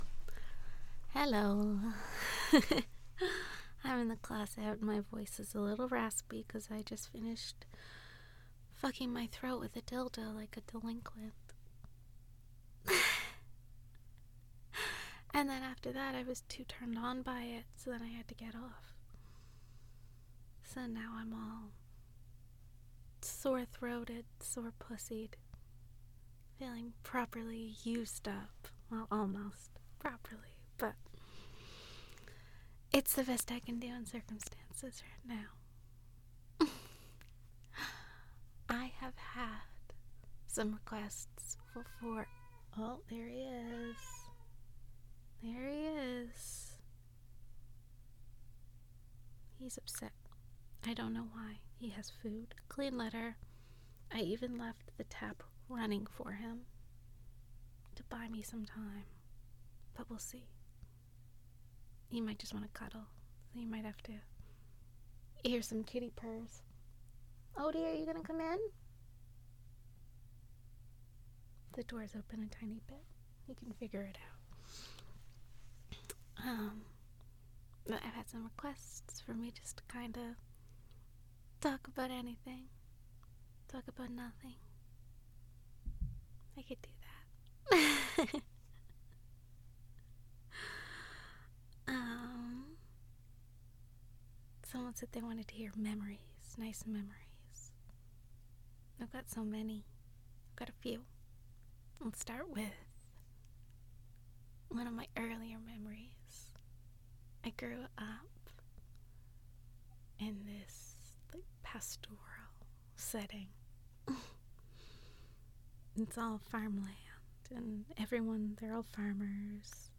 This is my attempt at talking about nothing really but hoping its soothing enough that you feel comforted and happy afterwards. Someone suggested I talk about good memories so I share some from my childhood in the country and Odin makes his first official appearance.